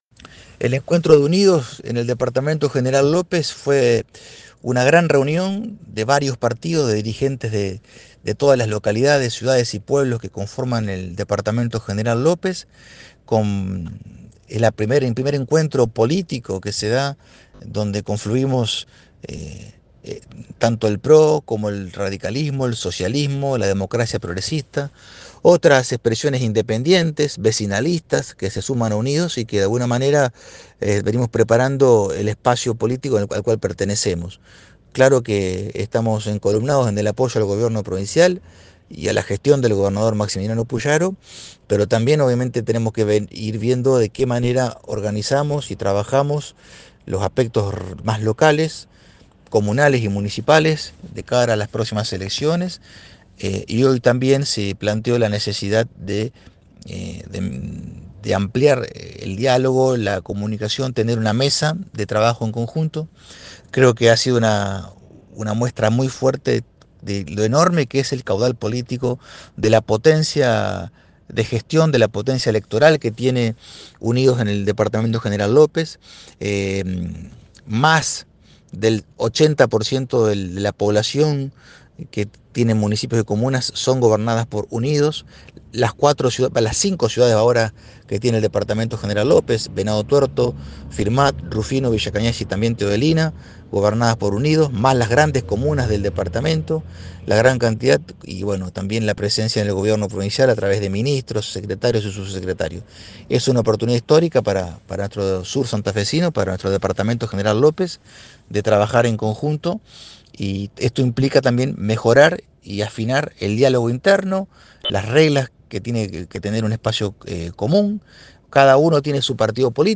Declaraciones del Ministro de Obras Públicas Lisandro Enrico